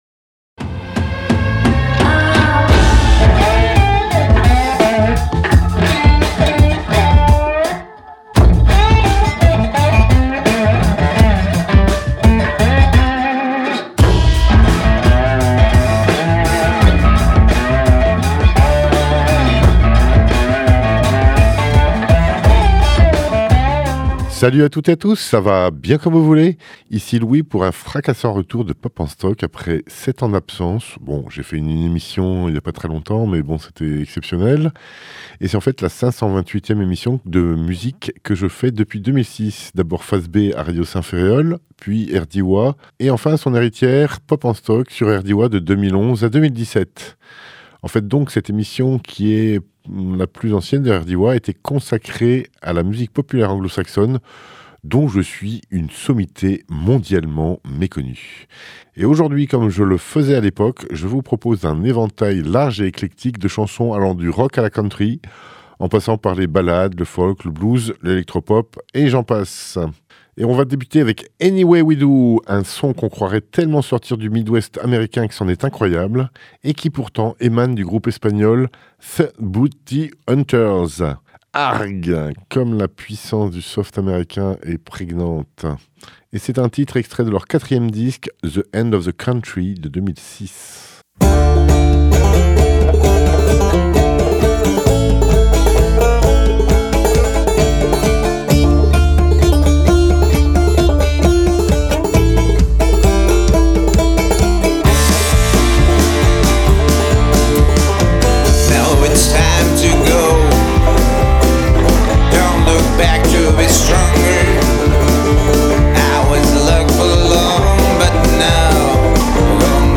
country , folk , musique electronique , rock